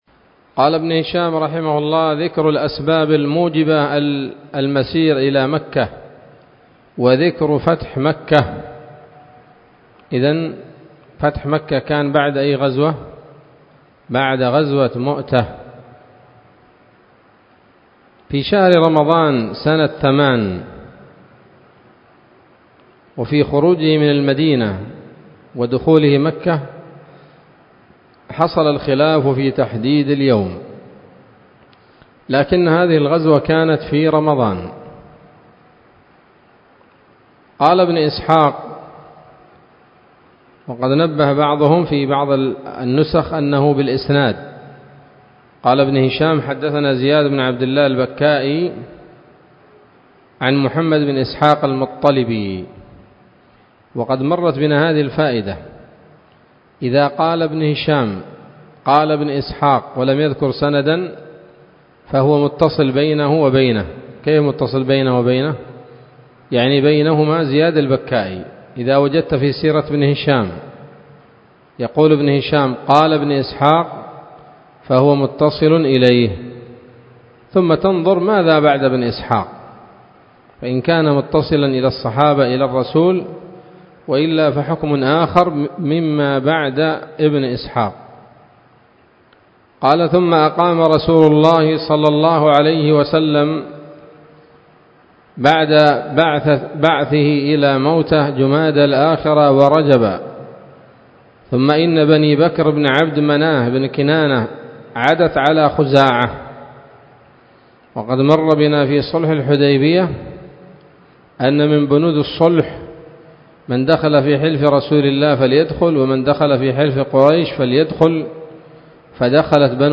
الدرس الرابع والخمسون بعد المائتين من التعليق على كتاب السيرة النبوية لابن هشام